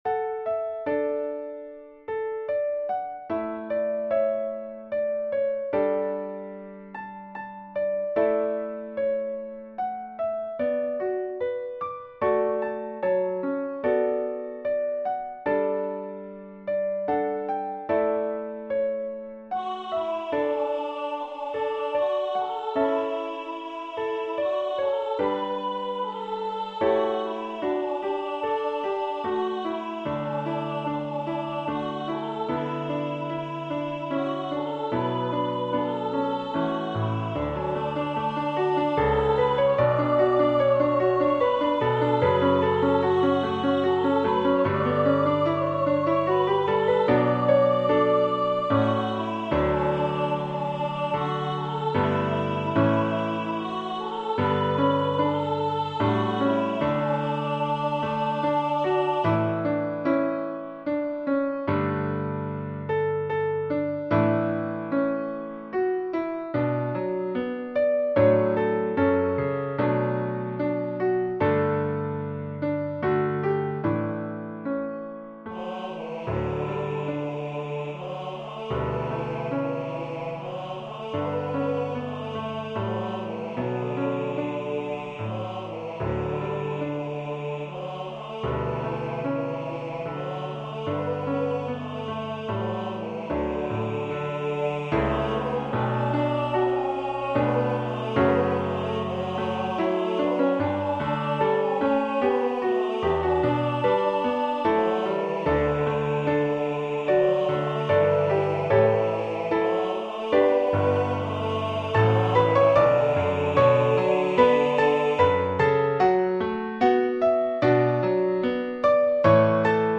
Come, Thou Fount of Every Blessing Hymn #1001 arranged as a Hymplicity-style hymn. SATB parts are straight out of the hymnbook.
Voicing/Instrumentation: SATB We also have other 73 arrangements of " Come, Thou Fount of Every Blessing ".